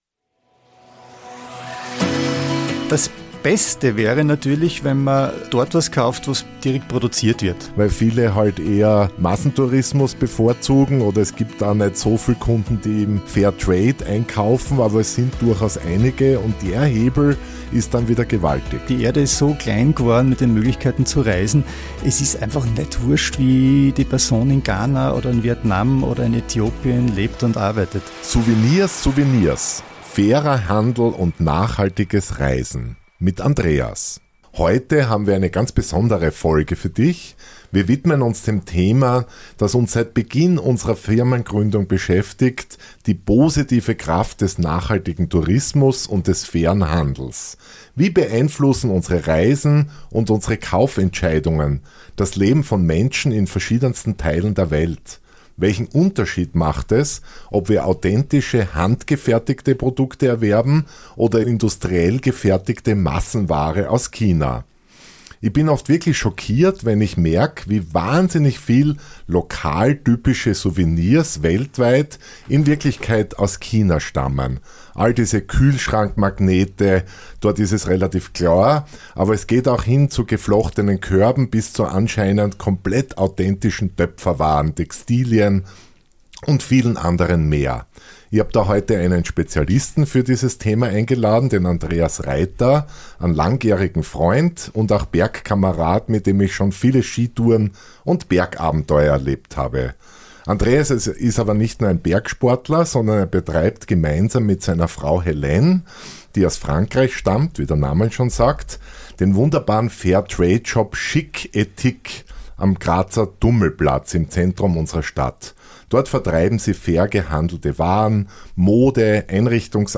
Ein Gespräch über die Kraft bewusster Kaufentscheidungen und authentische Reiseerlebnisse.